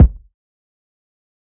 TC Kick 17.wav